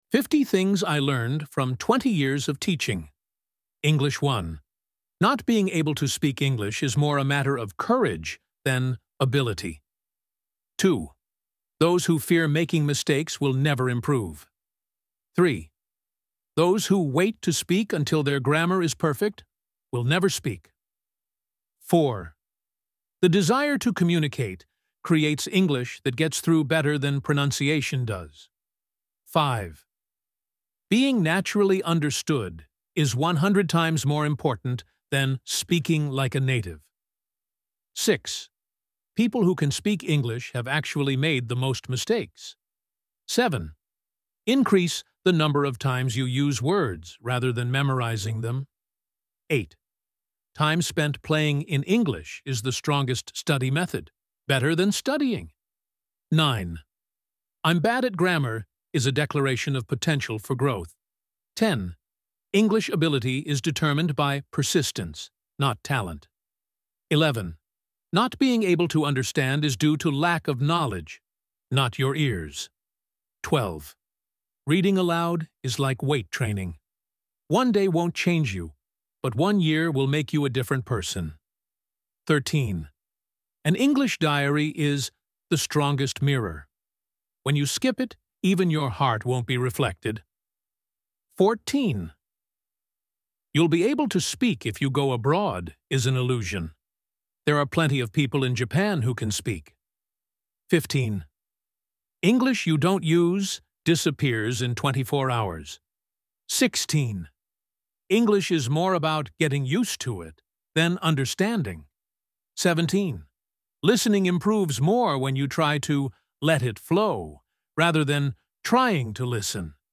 英語教師を20年やってわかったこと50選（英語版＋英語朗読音声つき）